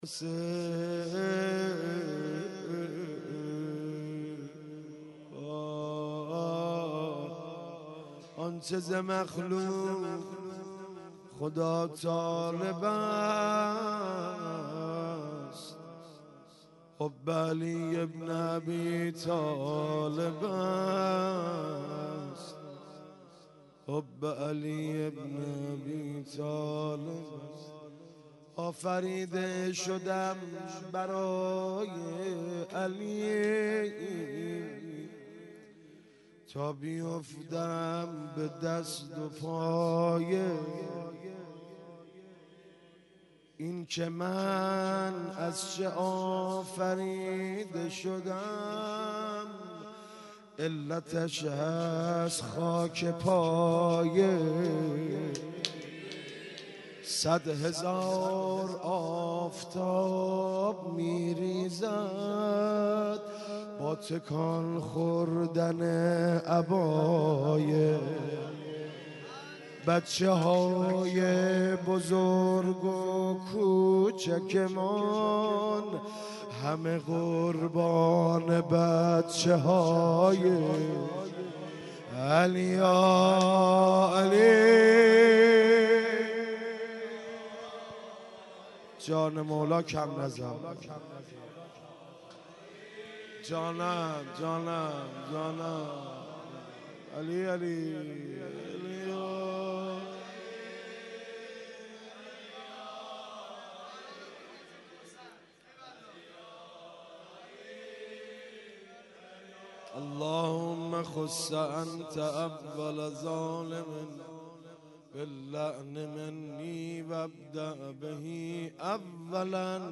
15- شعر خوانی آخر جلسه - کمیل.mp3
15-شعر-خوانی-آخر-جلسه-کمیل.mp3